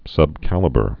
(sŭb-kălə-bər)